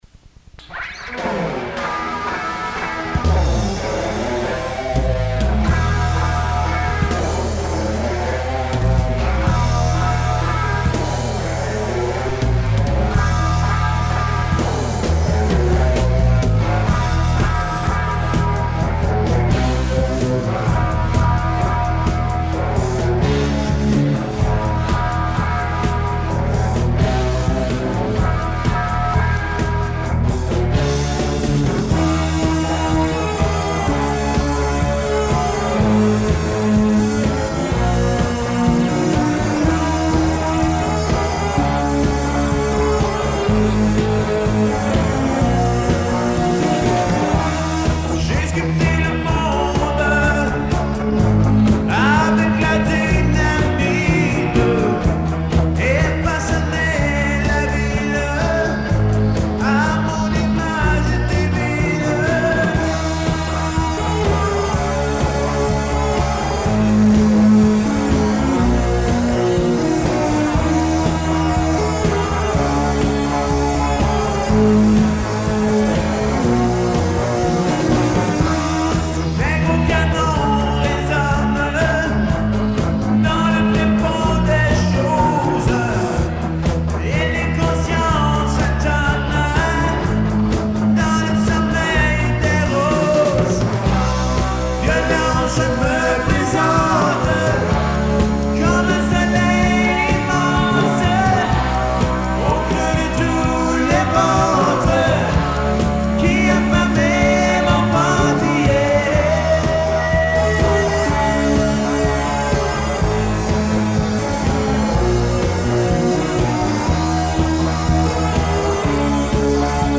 Enregistré en 1982 au local de GIBRALTAR.
Guitare, Voix
Batterie, Choeur
Claviers, Choeur
Basse